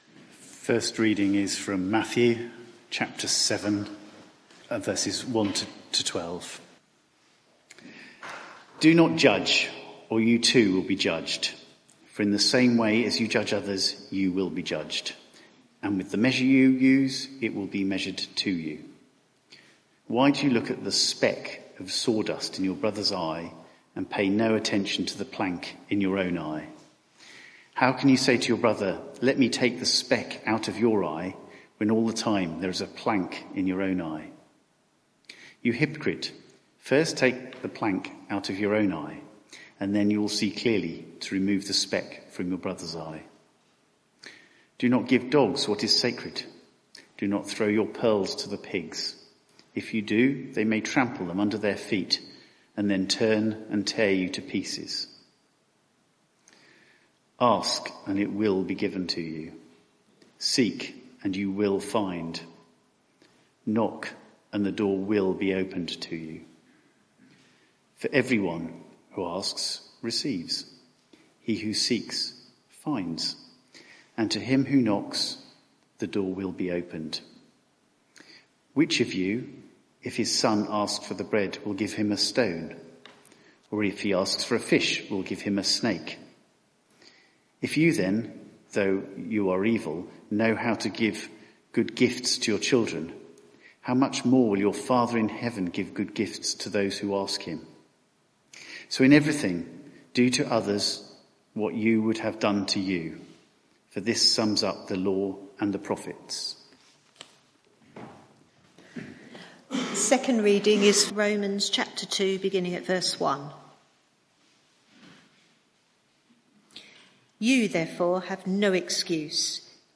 Media for 6:30pm Service on Sun 22nd Oct 2023 18:30 Speaker
Series: Sermon on the Mount Theme: Matthew 7:1-12 Sermon (audio)